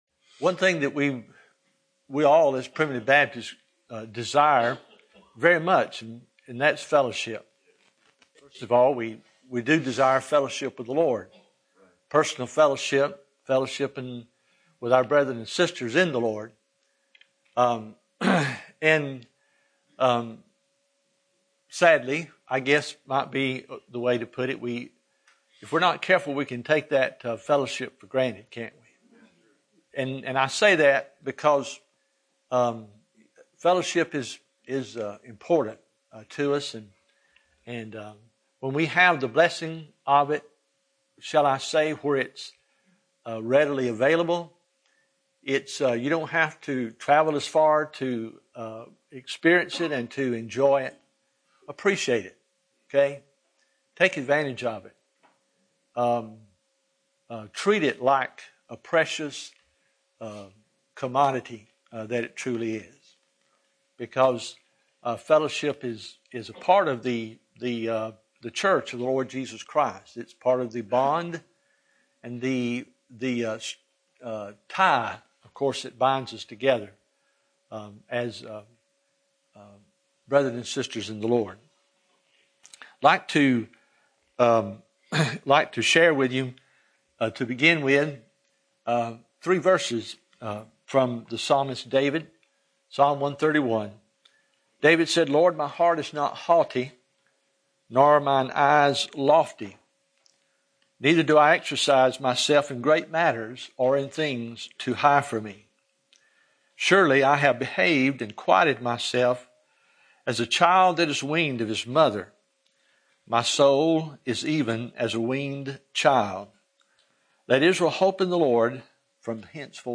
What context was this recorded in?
First Primitive Baptist Ass’n of OK – 2015 July 10, Friday Evening